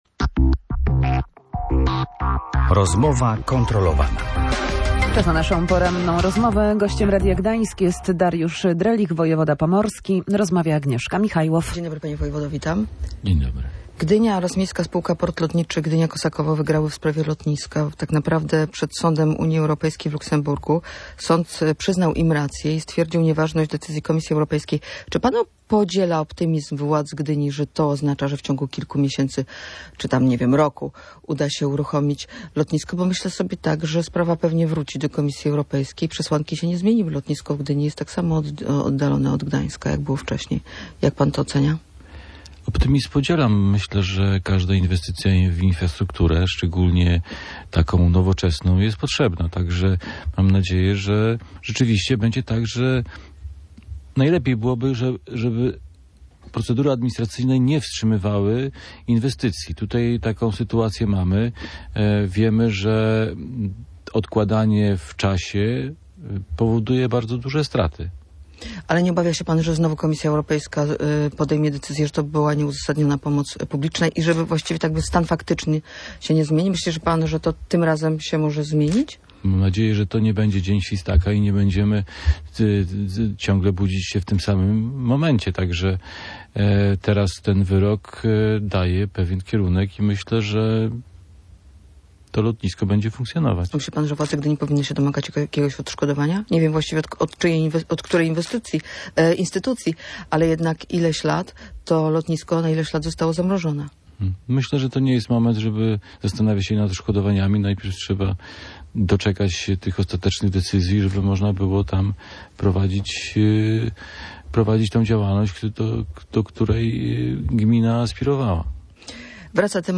– Najlepiej byłoby, żeby procedury administracyjne nie wstrzymywały inwestycji – mówił na antenie Radia Gdańsk Dariusz Drelich.
Gościem Rozmowy kontrolowanej był wojewoda pomorski.